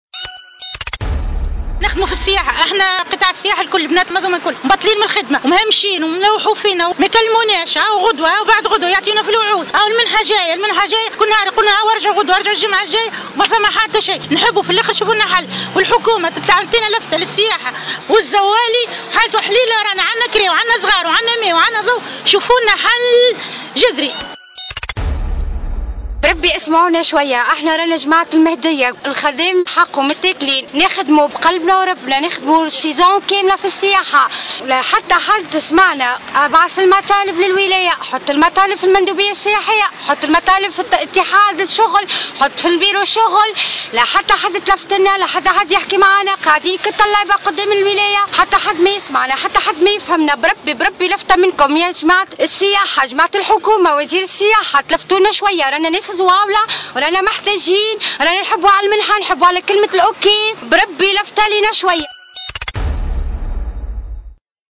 ويشتكي عملة السياحة من التهميش وعدم خلاص أجورهم لعدة أشهر وفق ما رصدته مراسلتنا في الجهة في الريبورتاج التالي :